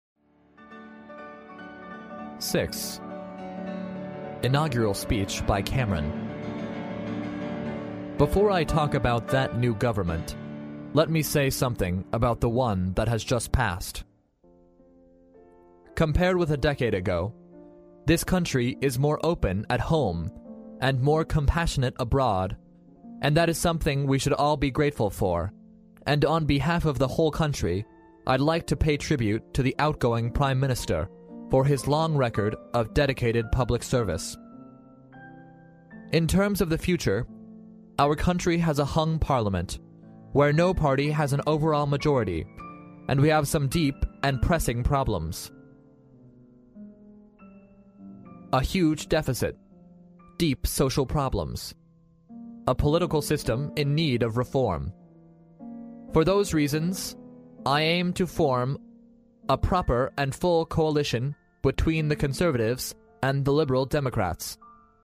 历史英雄名人演讲 第77期:卡梅伦就职演说(1) 听力文件下载—在线英语听力室